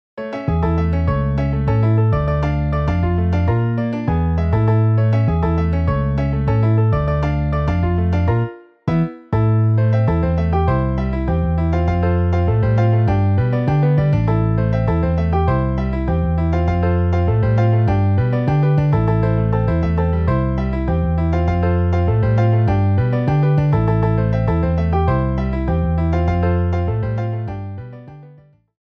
2X Trompetas, 2X Trombones, Piano, Bajo